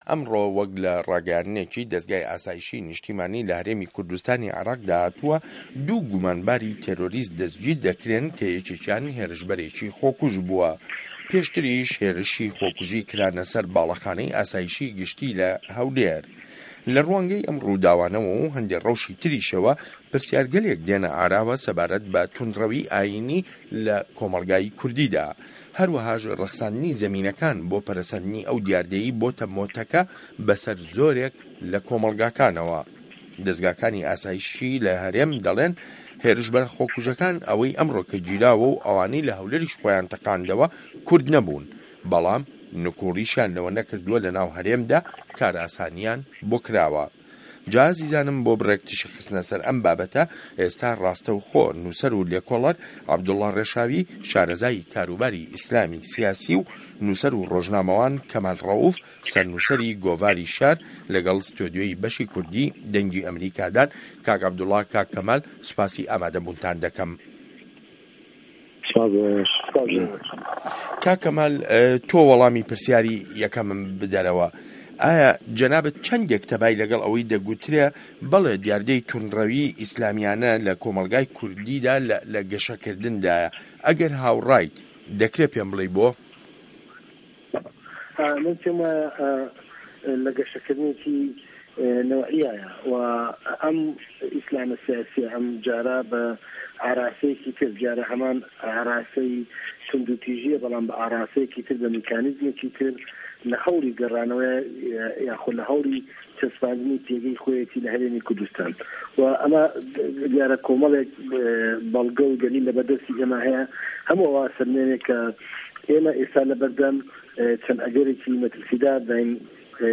مێزگرد : توندڕه‌وی ئاینی له‌ کۆمه‌ڵگای کوردیدا